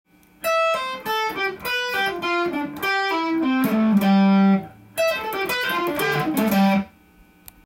tab譜のフレーズはAmキーで使用できます。
プリングとスィープピッキングを織り交ぜた左手が大変な